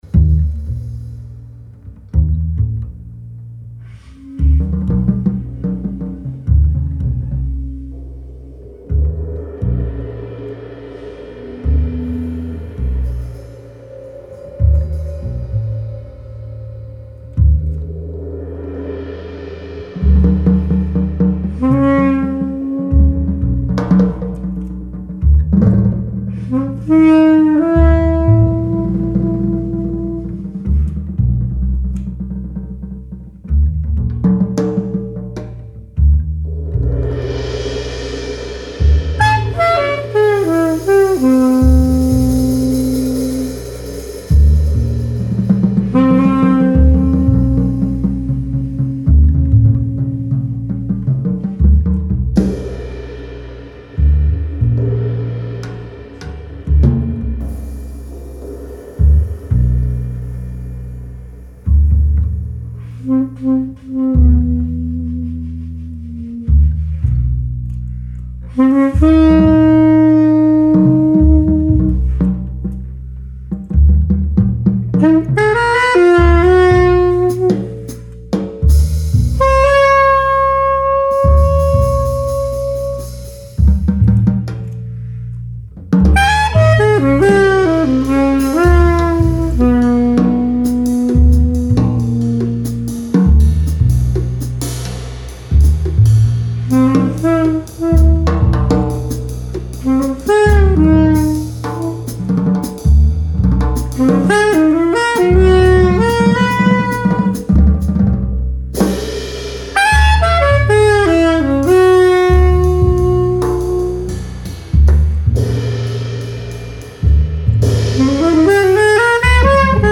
film score
reeds
drums